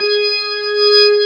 55O-ORG16-G#.wav